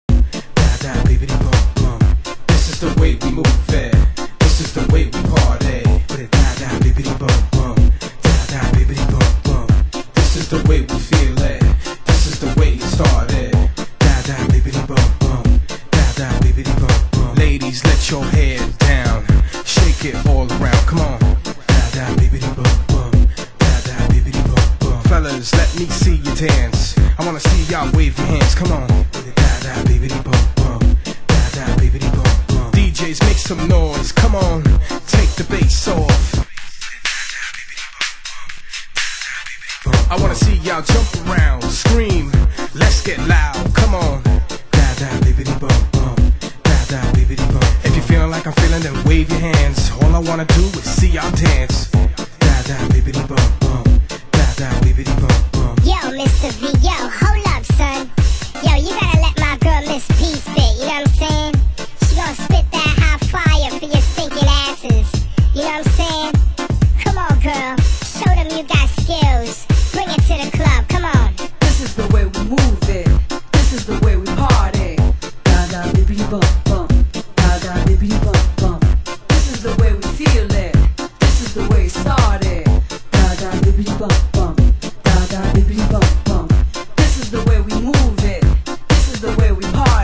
盤質：B面の盤面にシミ汚れがありますが、サウンドに大きな影響はありません(試聴箇所）/少しチリパチノイズ有　ジャケ：良好